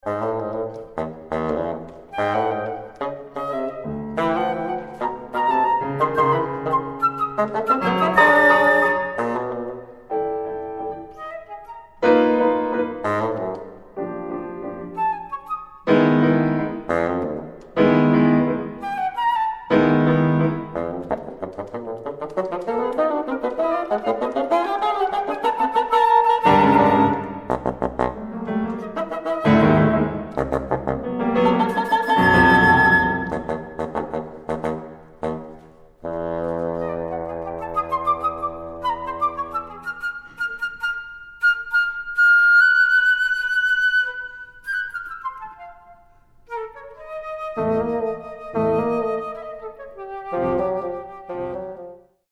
Fl., Bn, Pno